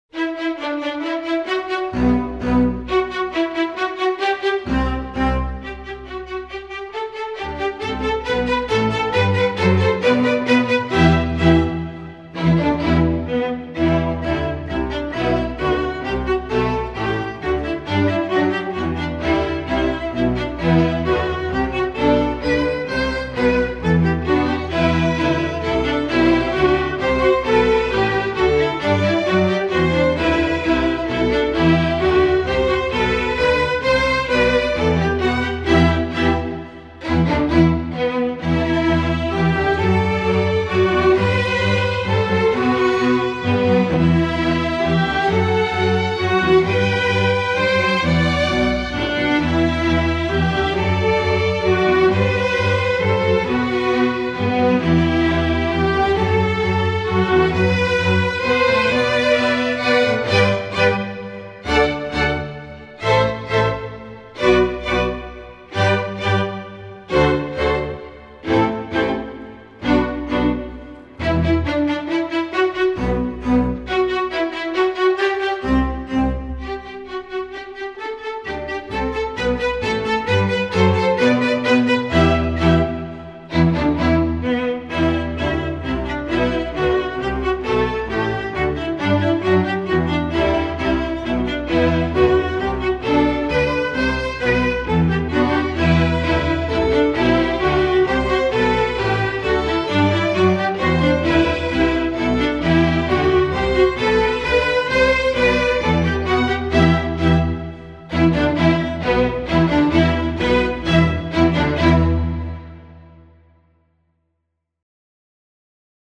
Voicing: String Orchestra W